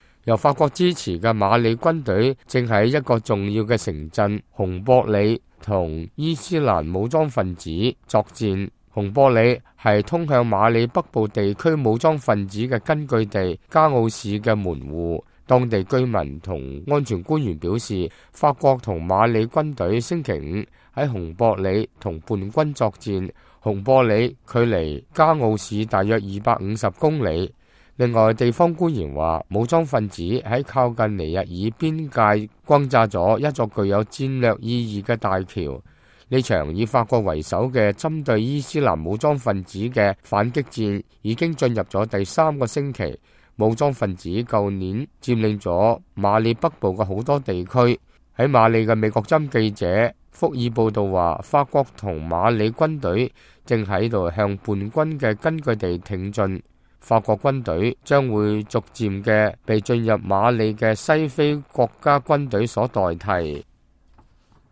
法國士兵星期五在法國南部的一個軍事基地的儀式中高唱國歌。